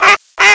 1 channel
00129_Sound_coin.mp3